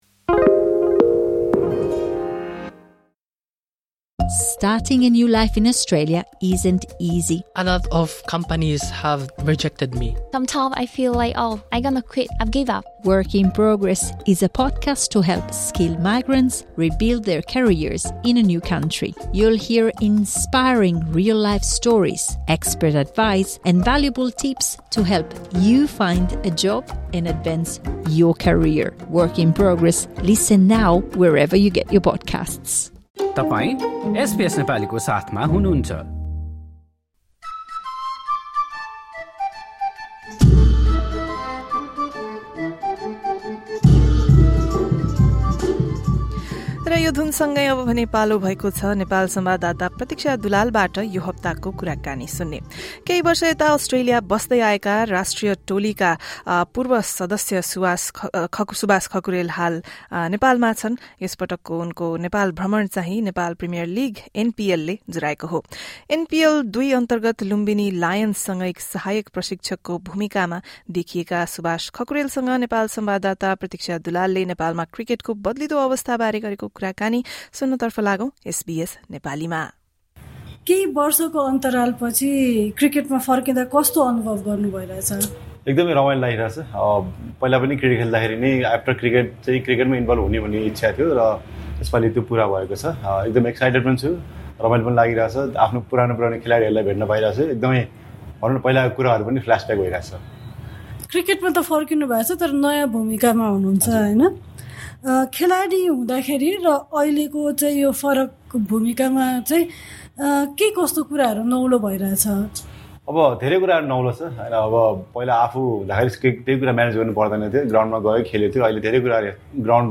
नेपालमा क्रिकेटको बद्लिँदो अवस्था बारे गरेको कुराकानी सुन्नुहोस्।